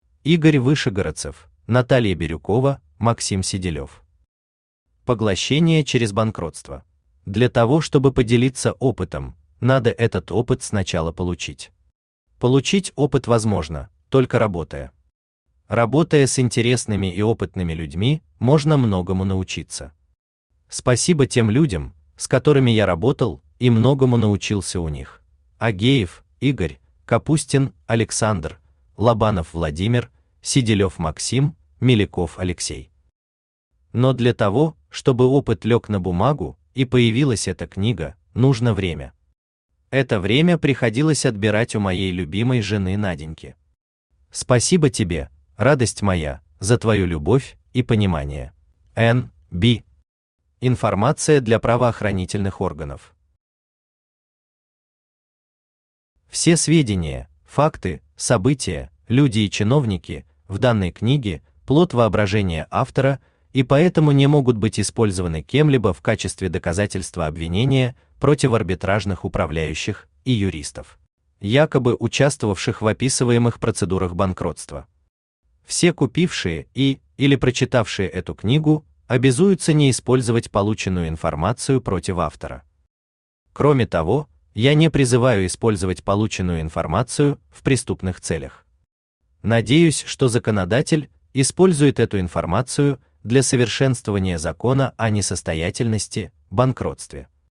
Аудиокнига Поглощение через банкротство | Библиотека аудиокниг
Aудиокнига Поглощение через банкротство Автор Игорь Вышегородцев Читает аудиокнигу Авточтец ЛитРес.